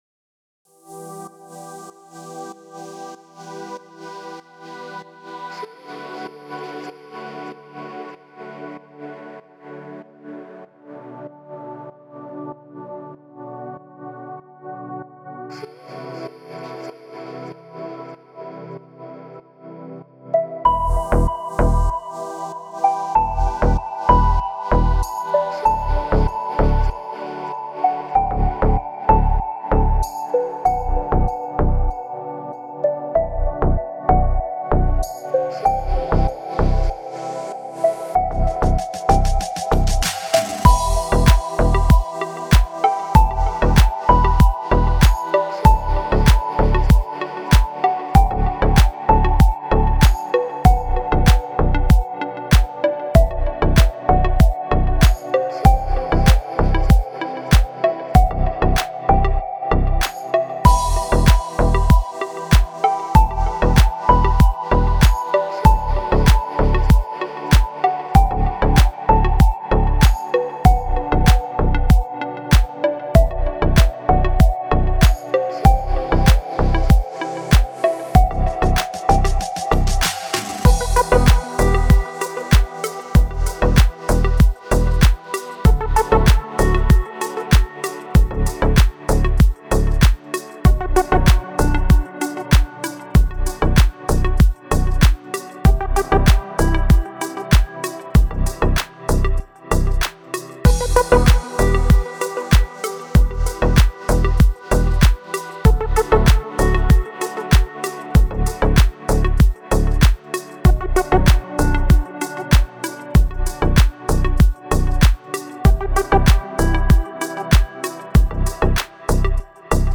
دیپ هاوس
ریتمیک آرام